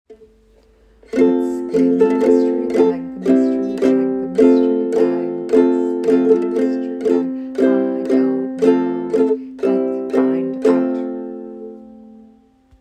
Tune: Mulberry Bush)
Shaker Songs